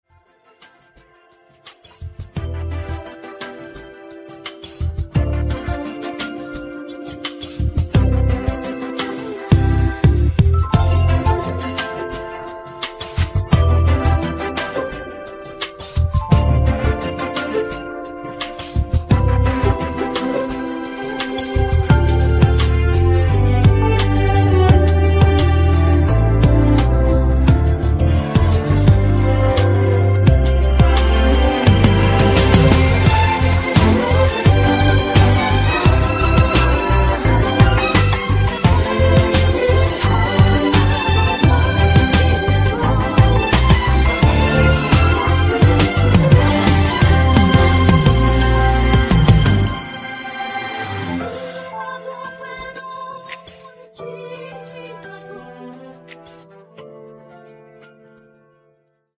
음정 원키 2:50
장르 가요 구분 Voice MR